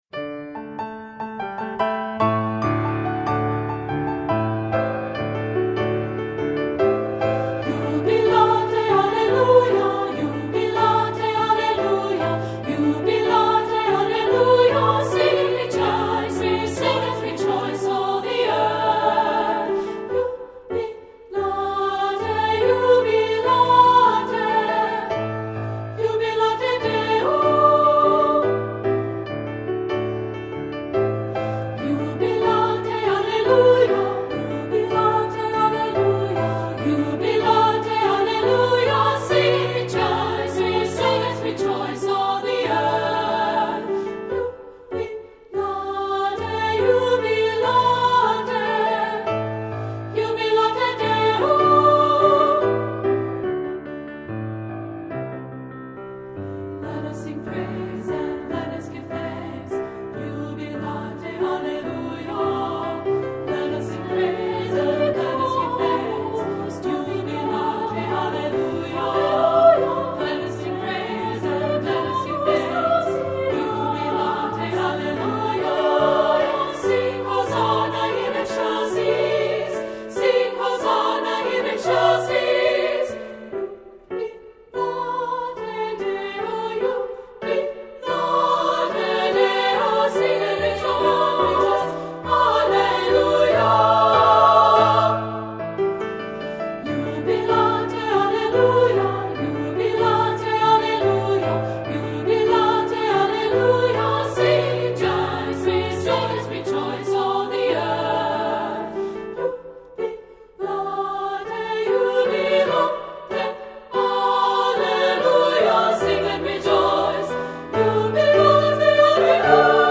Voicing: SSA and Piano